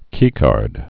(kēkärd)